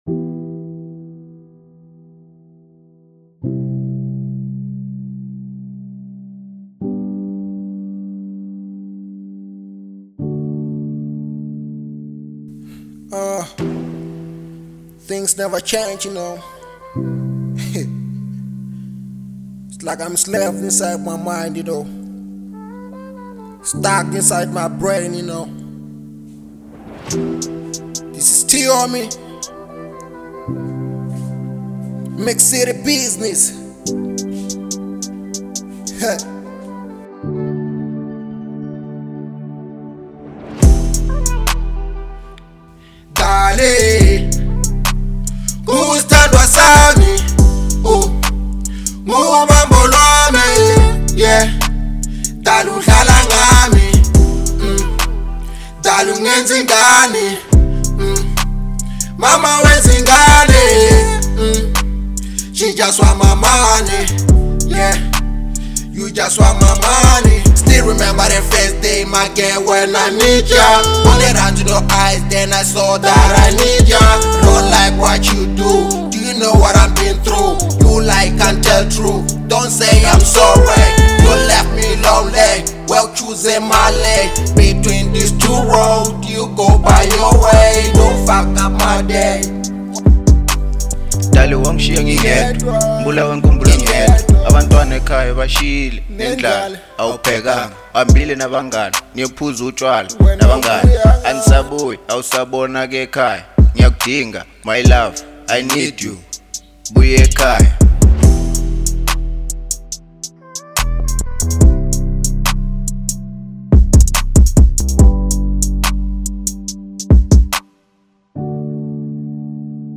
02:46 Genre : Trap Size